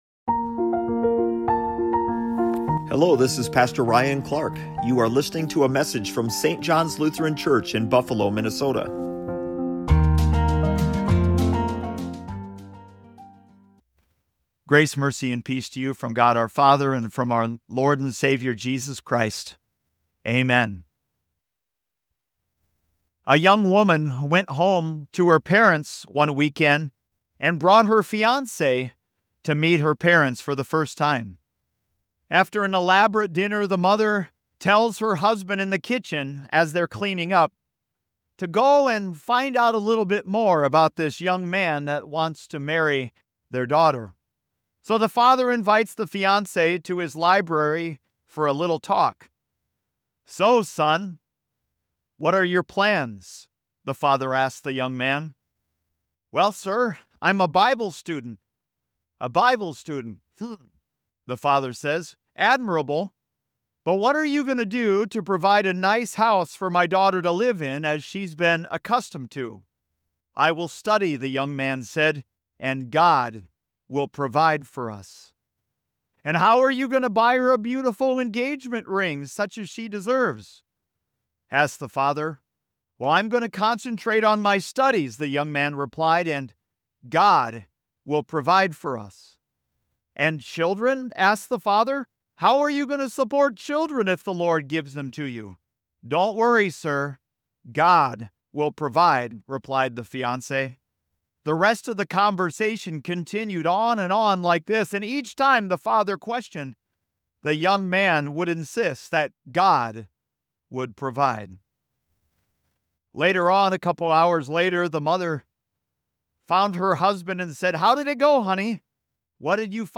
🌟 Listen now to the conclusion of the "Good to His Word" sermon series